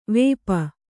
♪ vēpa